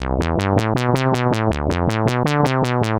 Track 16 - Synth 04.wav